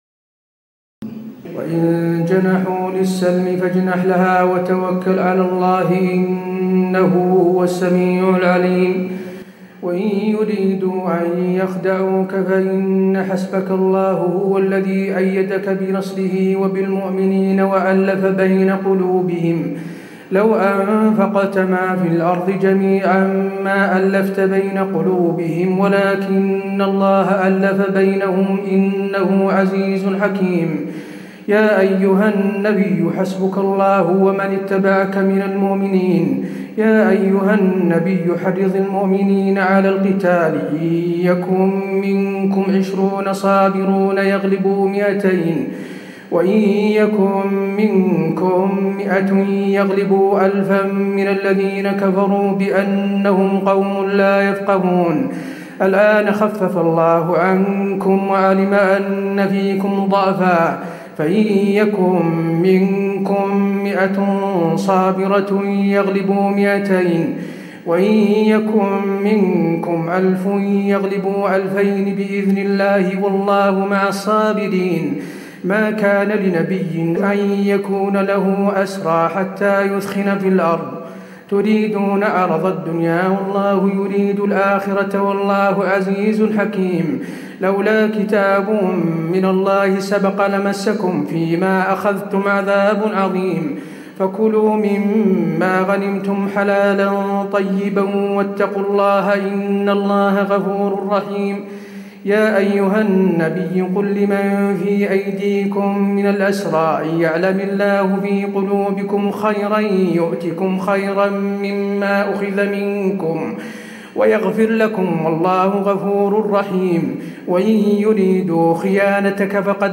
تراويح الليلة العاشرة رمضان 1436هـ من سورتي الأنفال (61-75) و التوبة (1-59) Taraweeh 10 st night Ramadan 1436H from Surah Al-Anfal and At-Tawba > تراويح الحرم النبوي عام 1436 🕌 > التراويح - تلاوات الحرمين